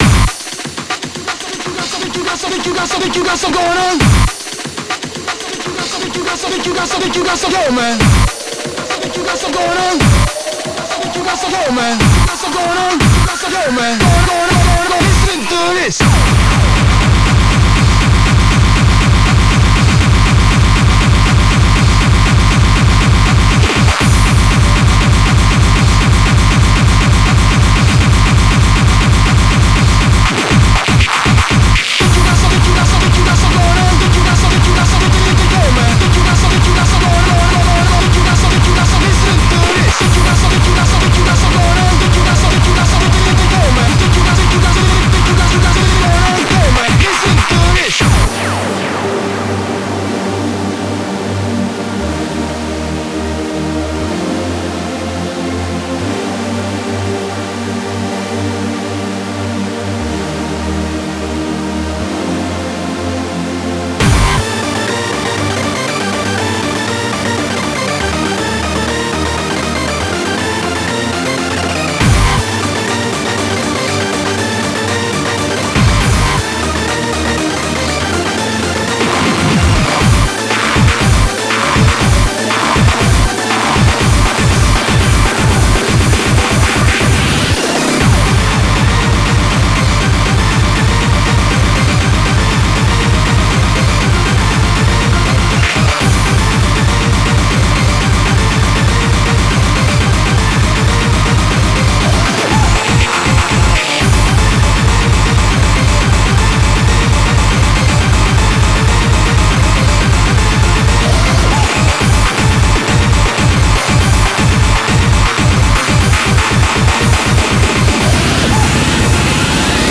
data/localtracks/Japanese/J-Core